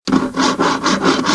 1 channel
scie_egoïne_02